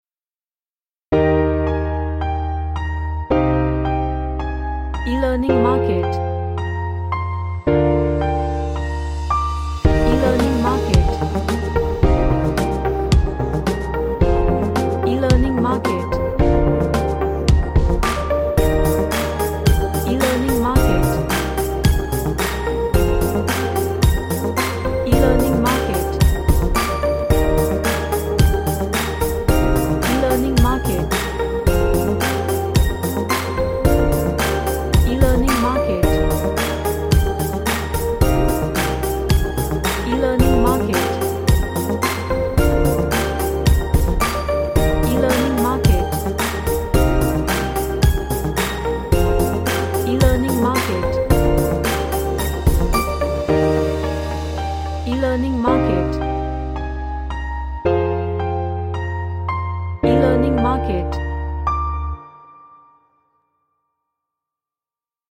HappyEmotional